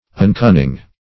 Search Result for " uncunning" : The Collaborative International Dictionary of English v.0.48: Uncunning \Un*cun"ning\, a. Ignorant.